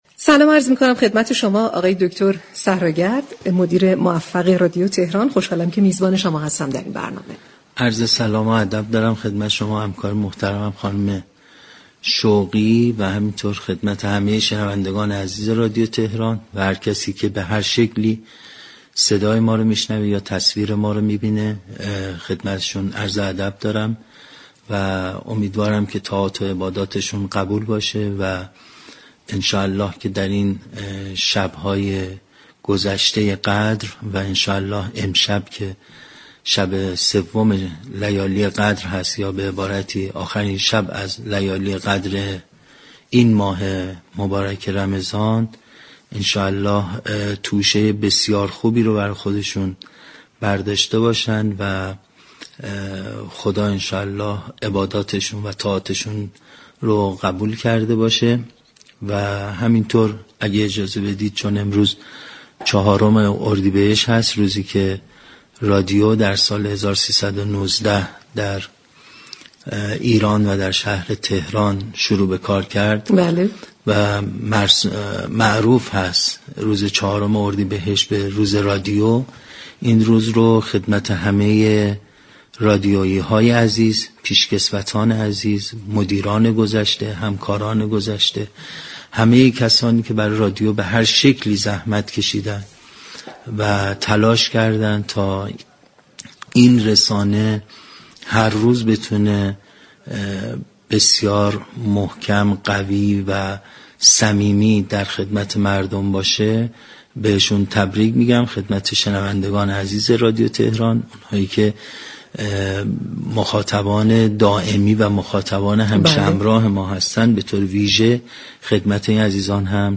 روز ملی رادیو در استودیو پخش زنده رادیو تهران حضور یافت و از برنامه پل مدیریت با شنوندگان رادیو تهران سخن گفت.